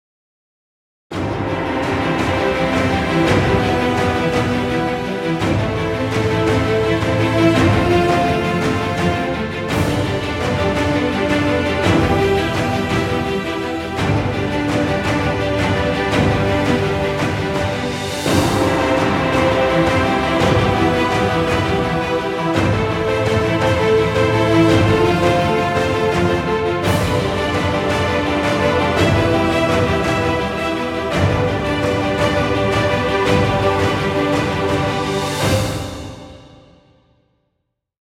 Trailer music, exciting intro, or battle scenes.